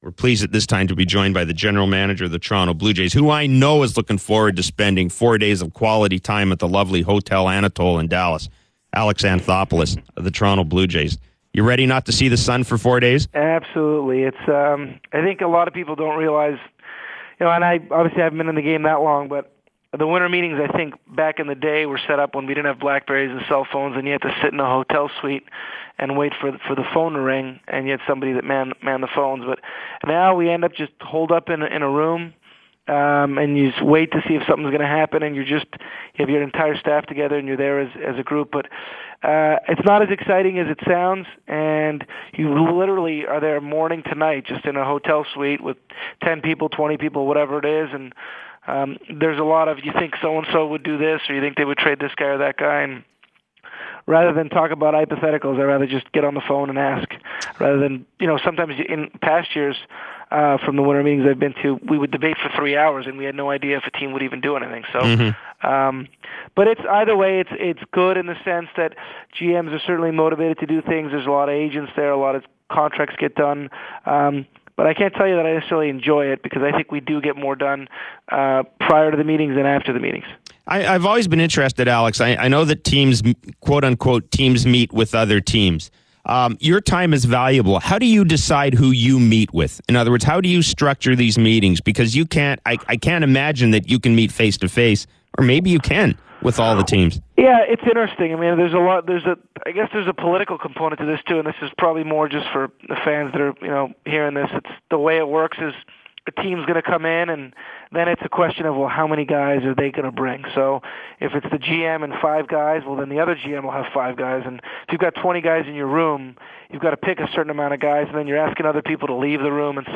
That’s the impression the Toronto Blue Jays general manager gave during an appearance Thursday on the Jeff Blair Show on Sportsnet Radio Fan 590.